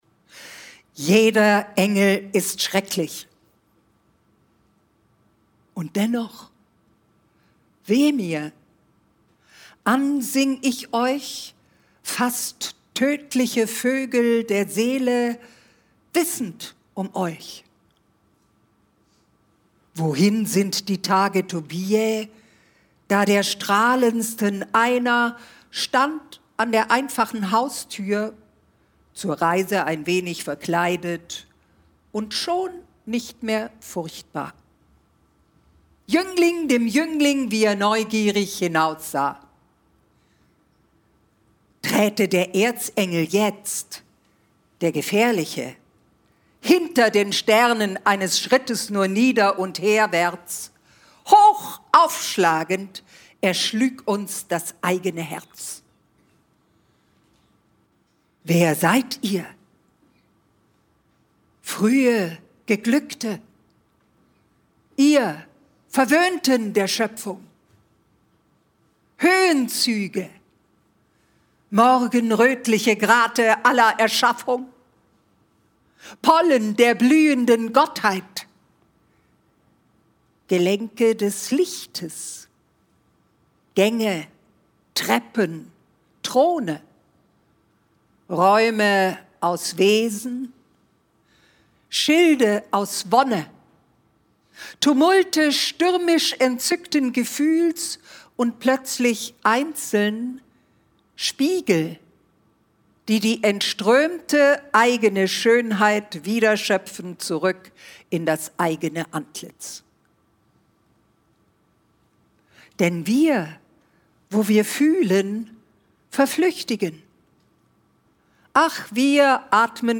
Freie Rezitation von Rainer Maria Rilkes »Duineser Elegien«. Live-Mitschnitt
Edgar Selge, Franziska Walser (Sprecher)
Sie lesen sie nicht vor, sondern lassen sich vom Augenblick leiten und rezitieren die Elegien frei.